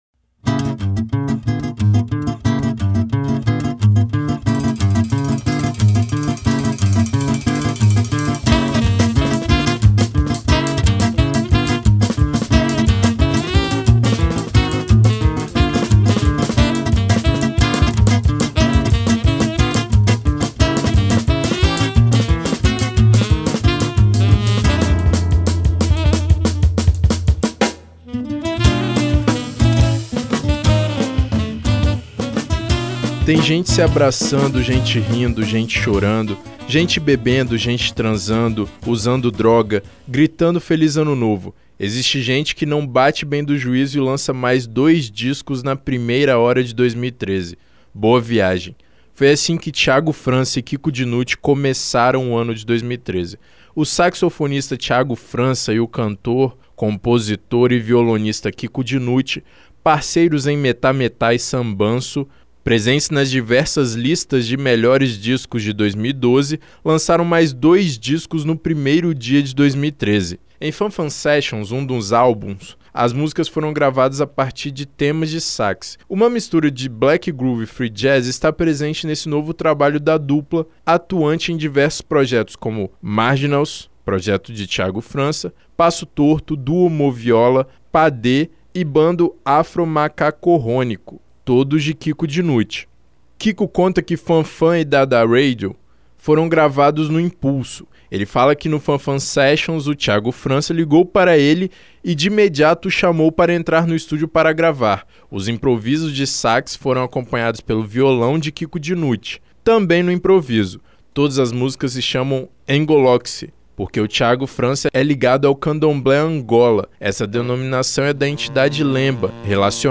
Acompanhe a matéria que o Revista Universitária preparou sobre esse mais novo projeto de Kiko Dinucci e Thiago França.